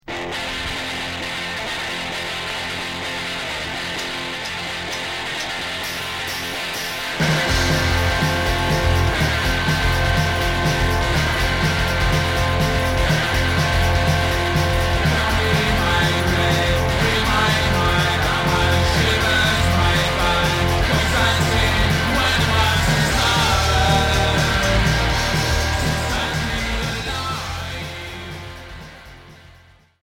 Rock garage punk